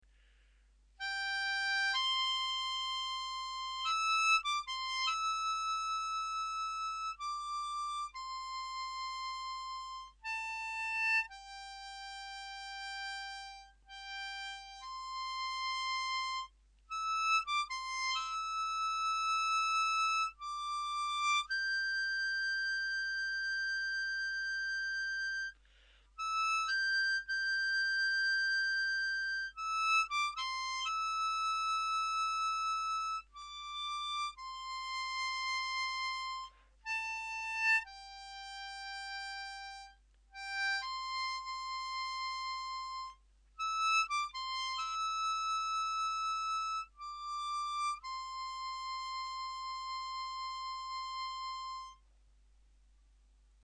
Using a C major 10 hole diatonic, we knew that the tune can be played using 7B as the root. But while this avoids any nasty bends, it does sound rather shrill.
Try to play the notes cleanly and with full tone.
High-end-clean.mp3